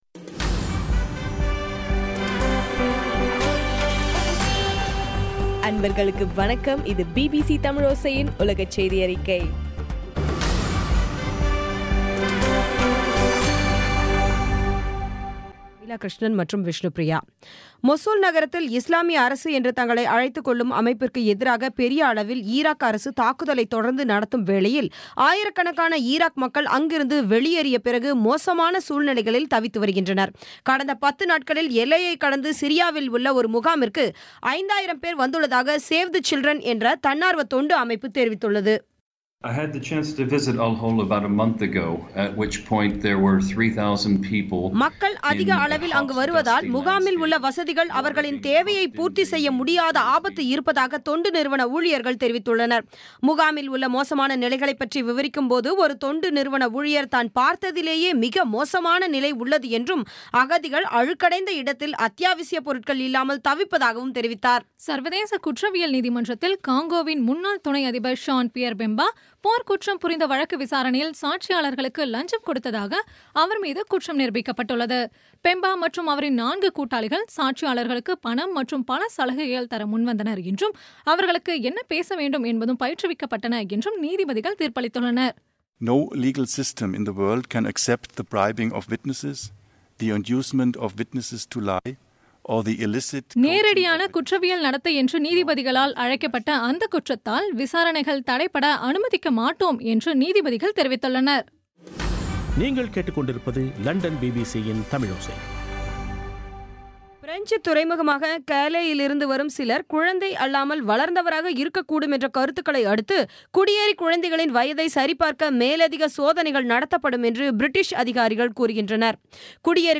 பி பி சி தமிழோசை செய்தியறிக்கை (19/10/2016)